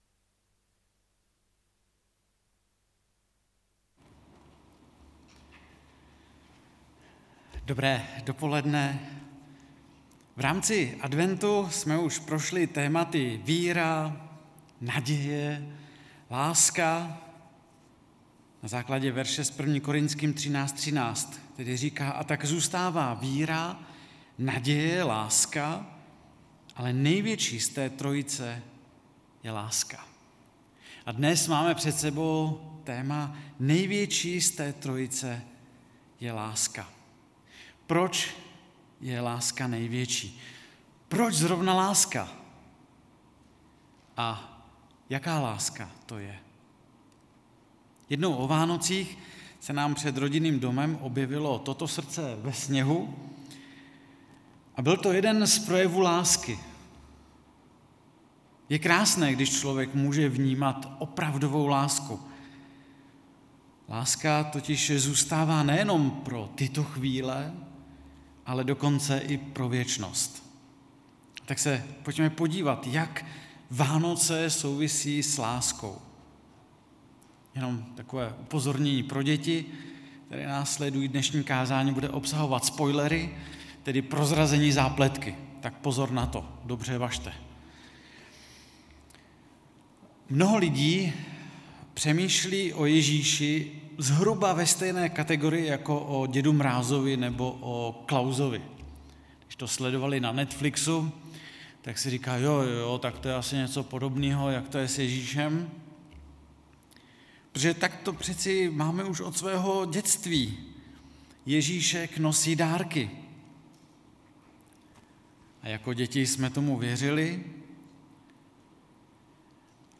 4. kázání ze série: víra, naděje, láska - ale největší z té trojice je láska.
Kategorie: Bohoslužba